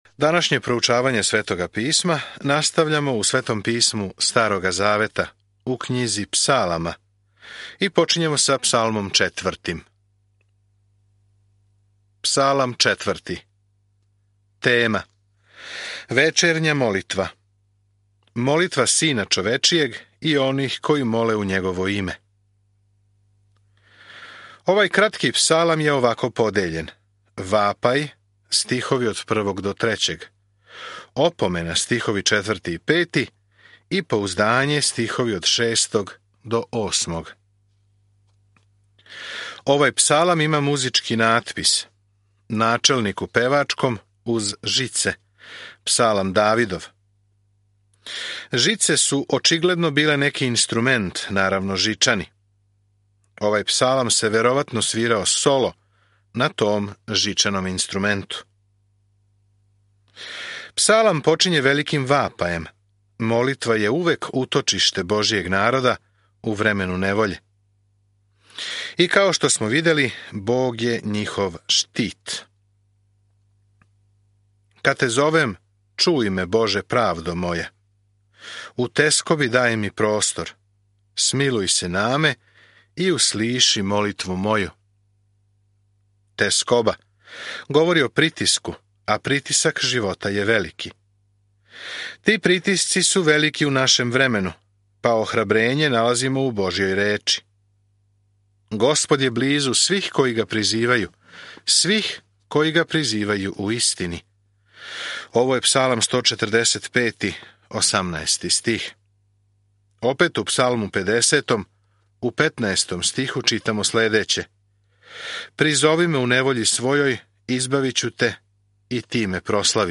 Sveto Pismo Psalmi 4 Psalmi 5 Dan 5 Započni ovaj plan Dan 7 O ovom planu Псалми нам дају мисли и осећања низа искустава са Богом; вероватно сваки од њих је првобитно постављен на музику. Свакодневно путујте кроз псалме док слушате аудио студију и читате одабране стихове из Божје речи.